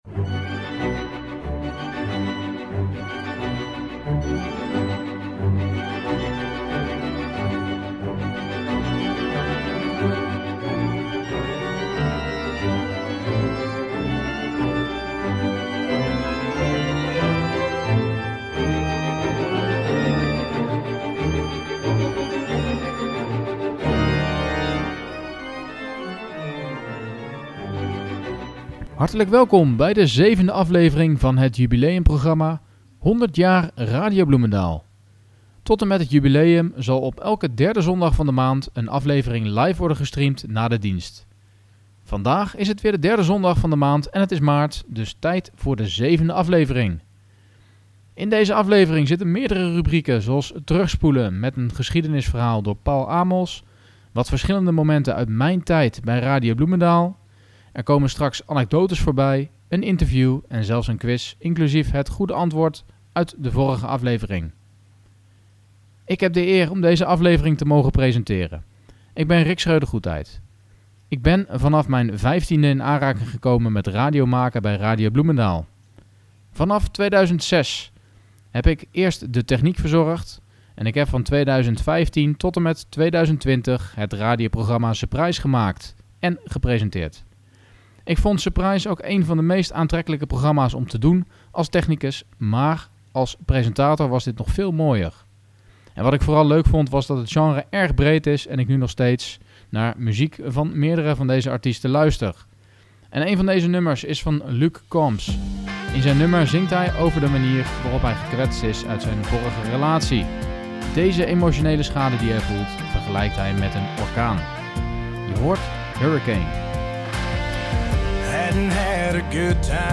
Tot slot een nieuwe aflevering van de tunesquiz!
In deze aflevering van 100 jaar RB dus veel karakteristieke ‘Surprise’-muziek!